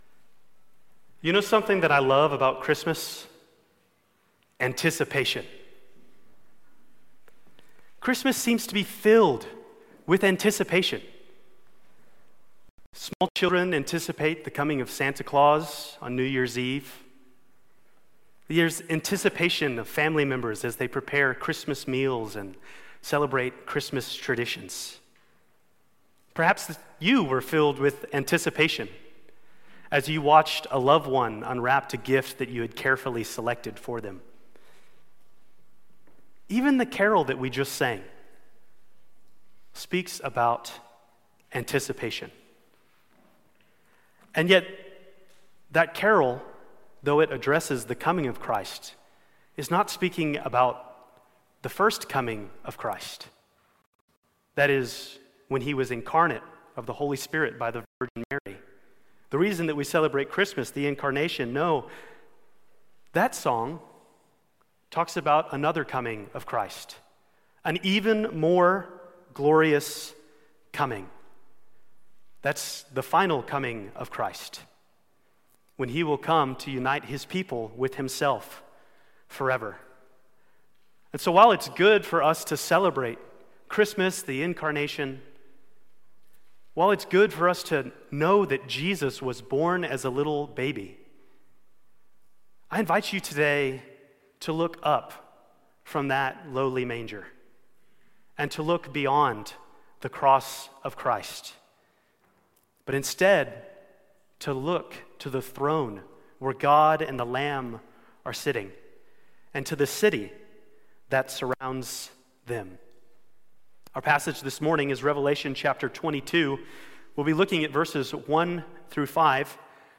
Stand Alone Sermons Passage